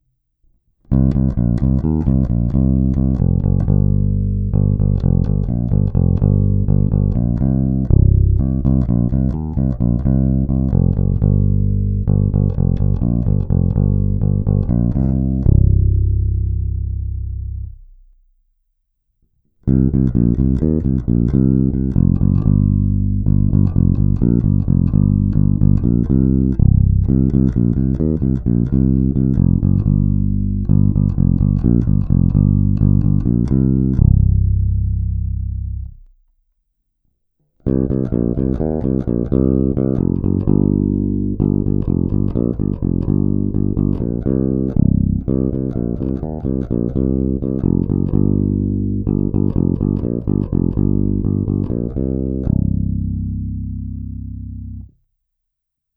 Zvuk má modernější projev, je pěkně tučný, se sametovými nižšími středy, ovšem taky je nepatrně zastřený díky použitým humbuckerům.
Není-li uvedeno jinak, následující nahrávky jsou provedeny rovnou do zvukové karty, s plně otevřenou tónovou clonou a na korekcích jsem trochu přidal jak basy, tak výšky.
Snímač u kobylky